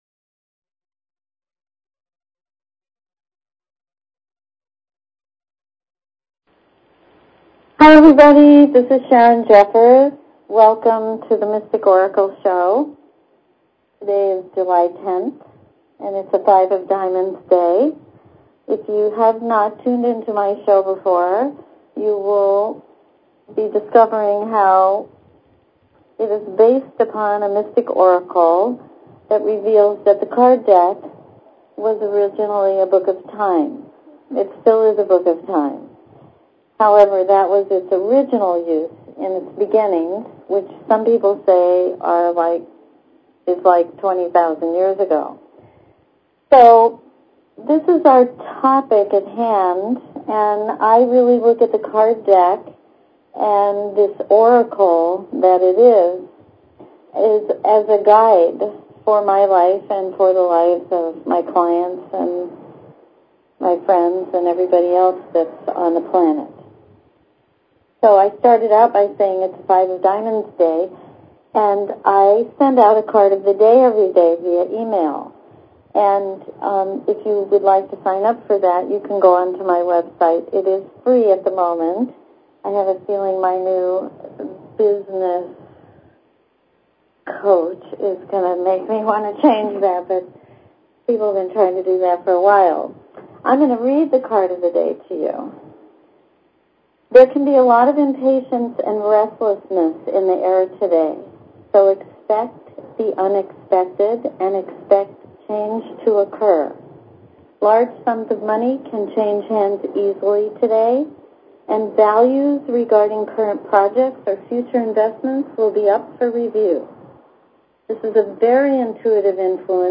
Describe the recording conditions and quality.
Open lines for calls.